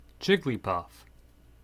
Jigglypuff (/ˈɪɡlipʌf/